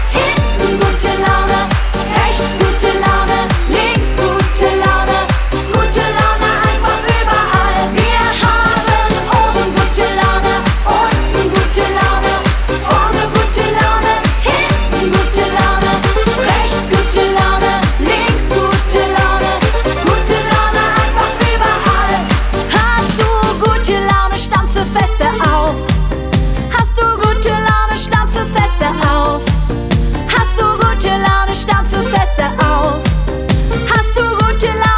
Жанр: Танцевальные
Children's Music, Dance